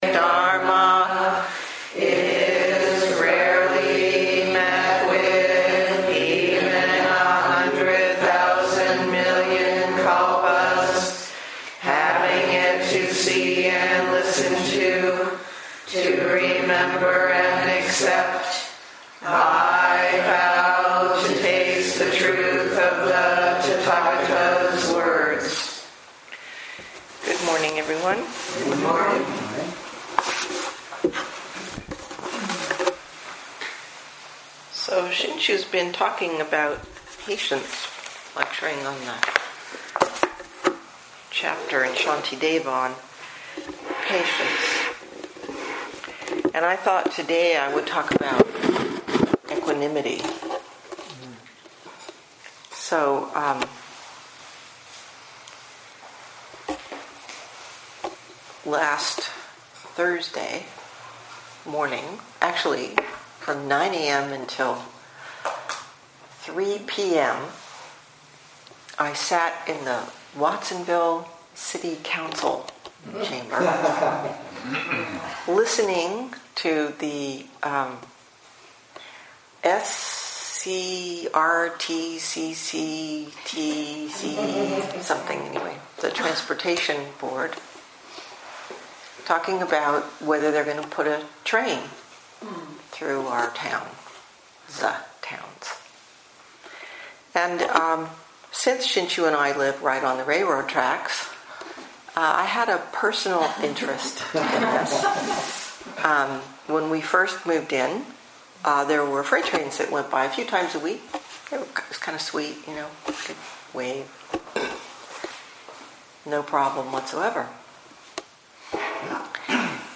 2018 in Dharma Talks